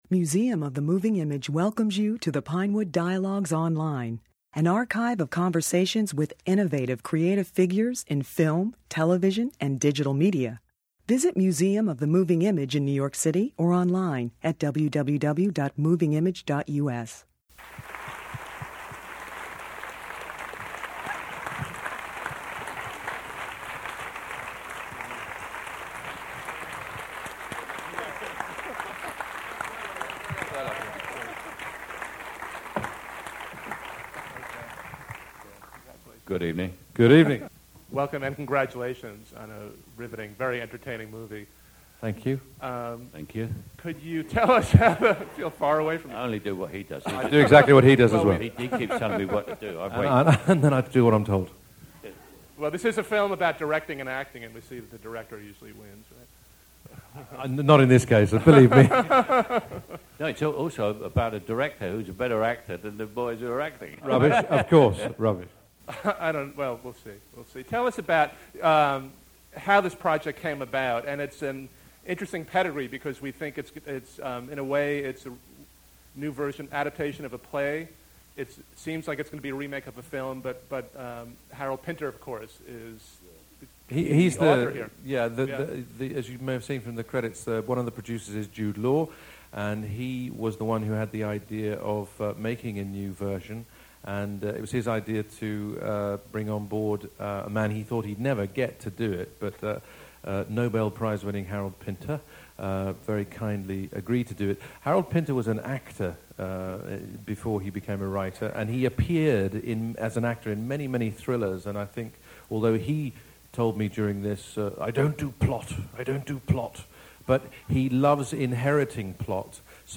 In this discussion, Branagh and Caine discussed their collaboration after a special preview screening.